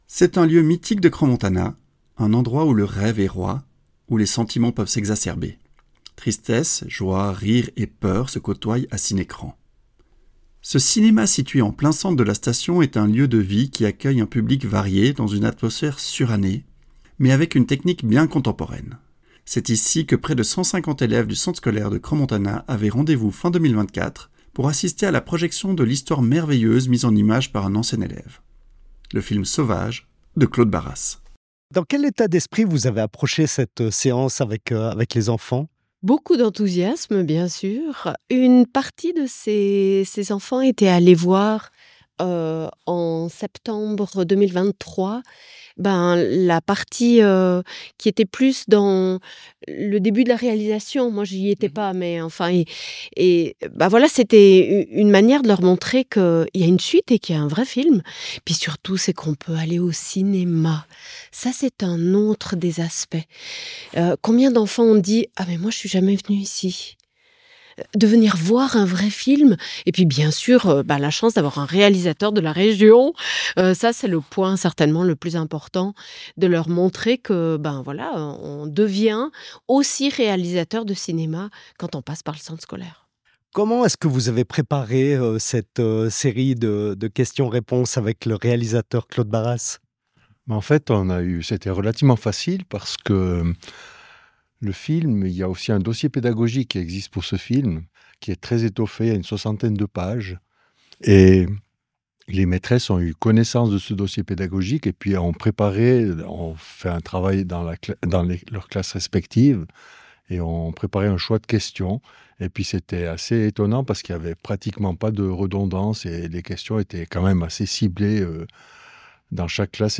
L'INFO a assisté à la projection scolaire de "Sauvages" et a profité de tendre son micro aux différentes personnes présentes.